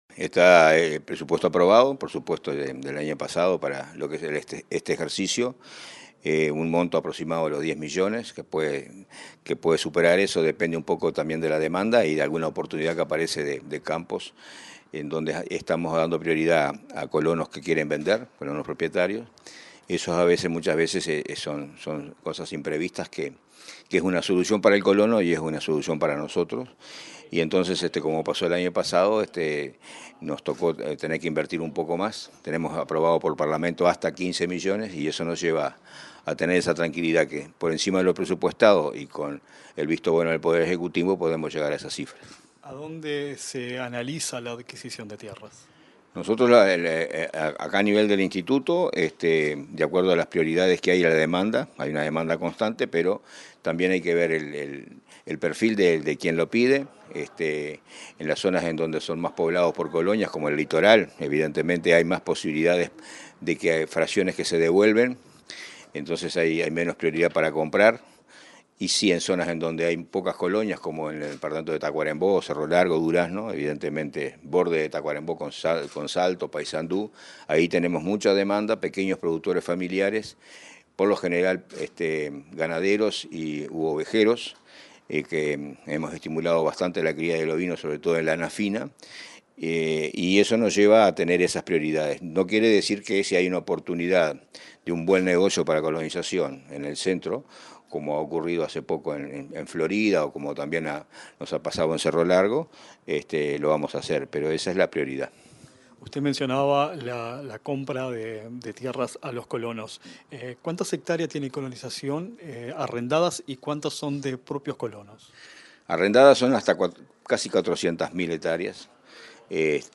Entrevista al director del INC, Julio Cardozo